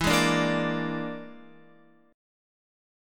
E7b5 chord